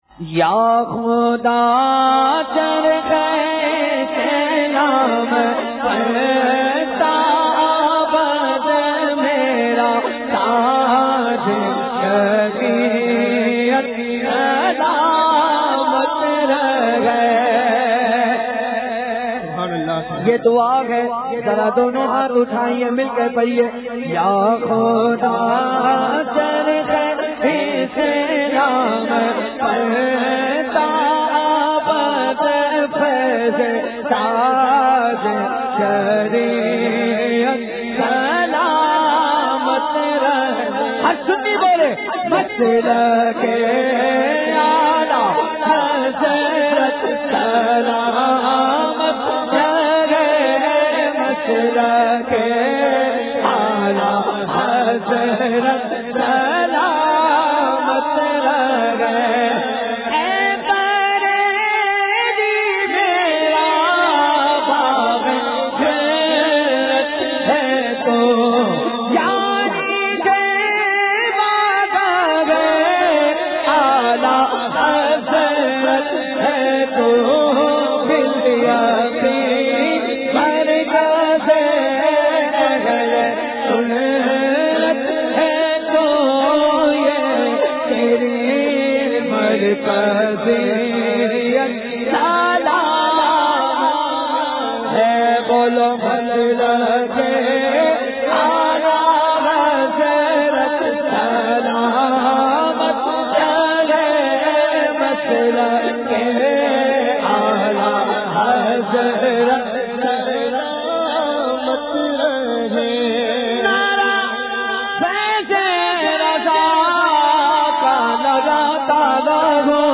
منقبت